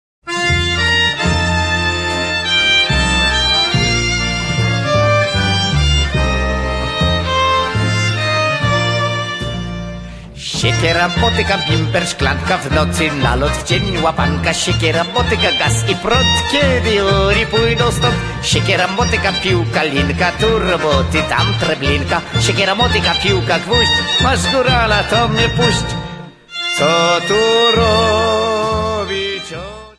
Polish songs